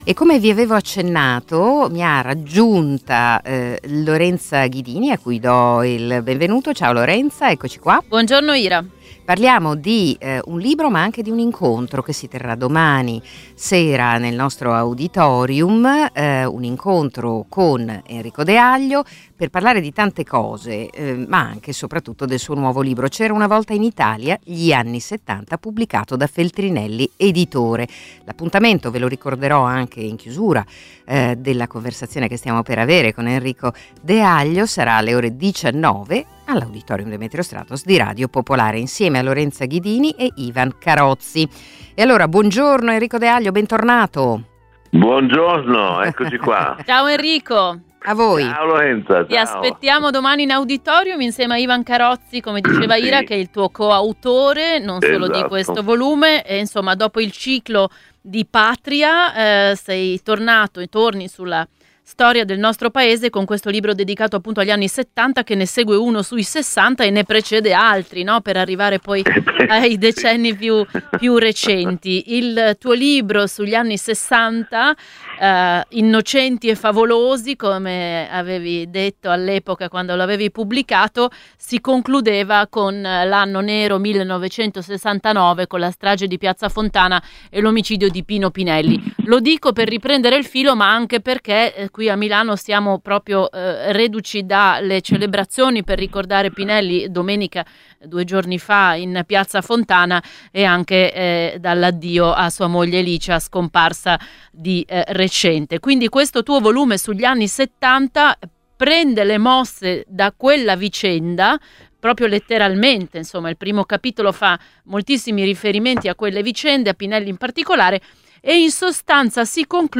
Mercoledì 18 dicembre 2024 alle ore 19.00 l’Auditorium Demetrio Stratos di Radio Popolare ospita lo scrittore e giornalista Enrico Deaglio, da poco...